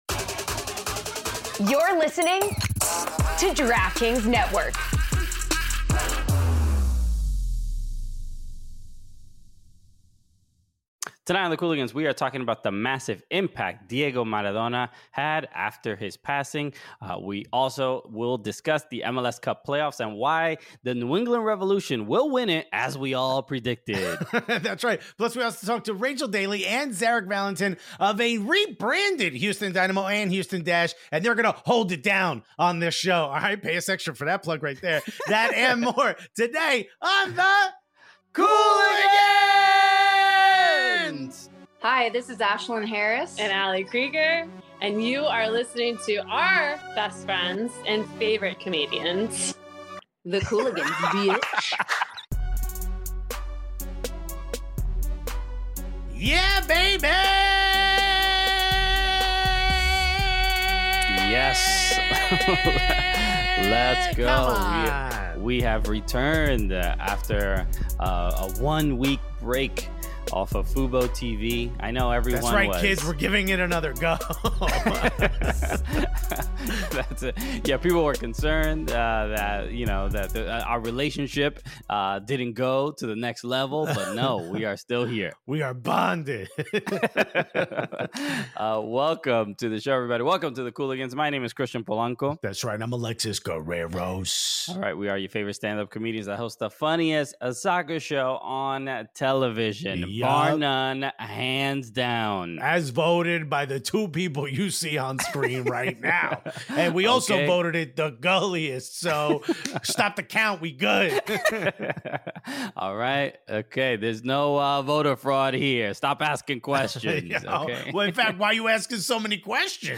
England International & Houston Dash Forward Rachel Daly and Houston Dynamo defender Zarek Valentin join us to talk about the big changes in Houston, Rachel's loan at West Ham, and we discuss the infamous match between England and the USA at the 2019 Women's World Cup. We also discuss the tributes to Diego Maradona from players all over the world.